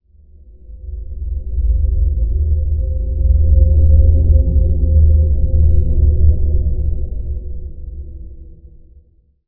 rumble3.wav